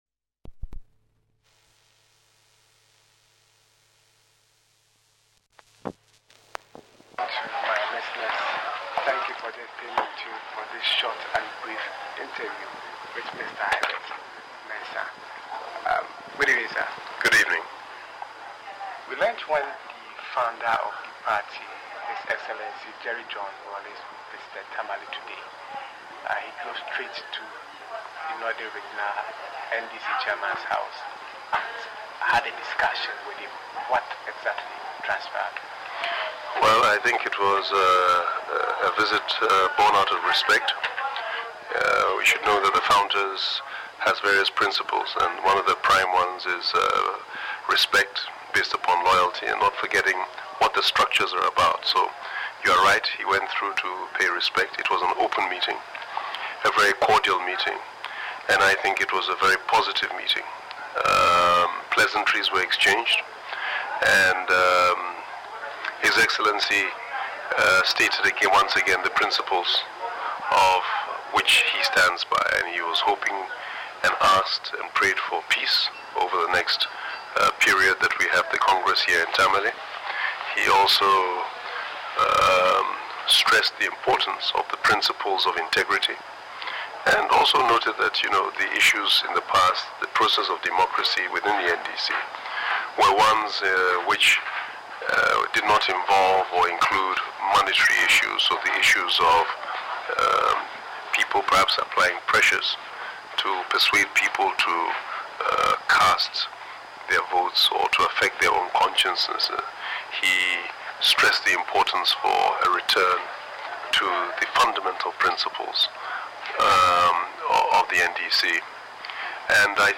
Interview
was held at the NDC National Delegates Conference in Tamale.